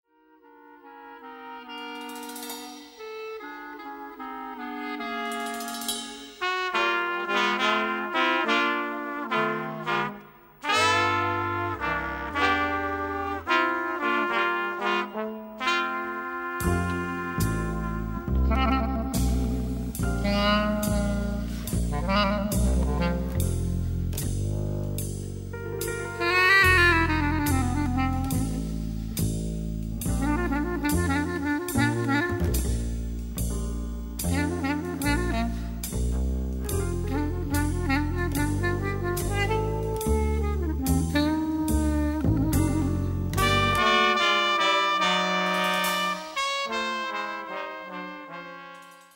who played piano throughout this 5-album series
cornet
trumpet
alto saxophone
baritone saxophone
vibraphone